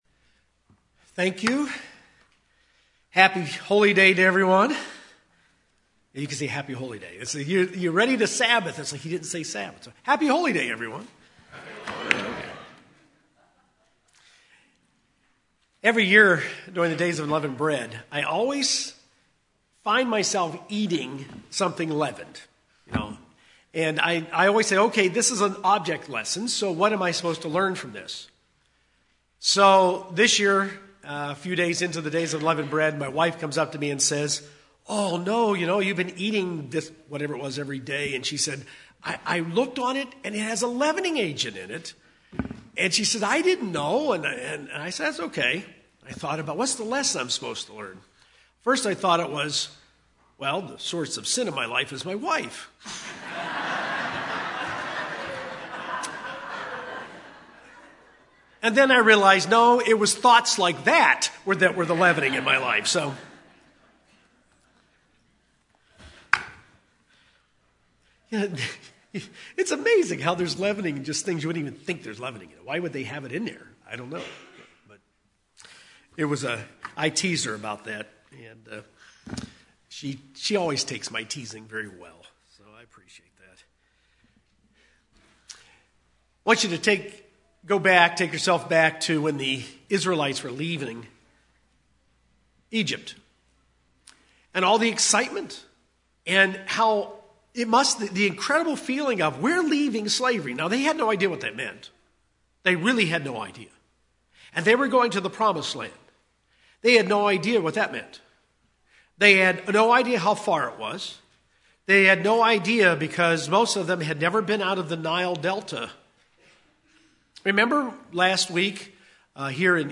God will sometimes lead us into places where there is no possible human solution. This is what He did with Israel leaving Egypt, and this sermon explores four lessons Christians can apply from their experience.